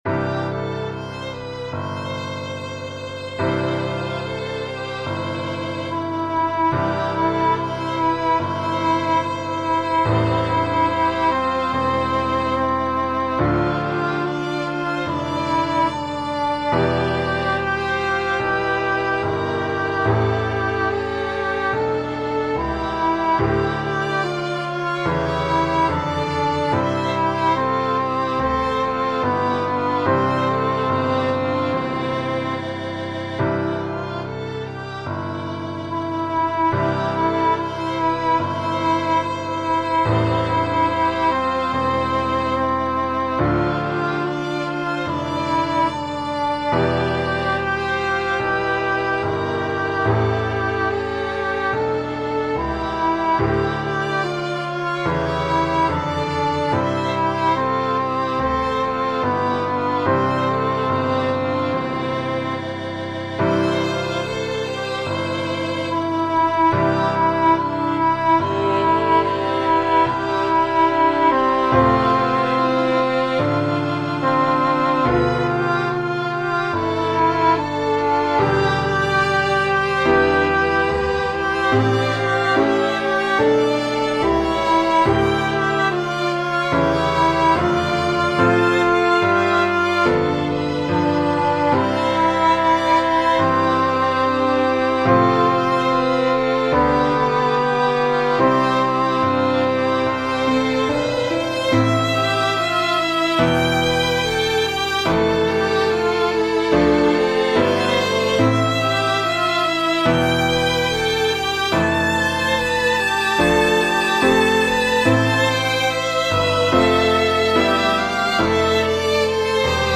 Violin Duet/Violin Ensemble Member(s)
Vocal Solo Medium Voice/Low Voice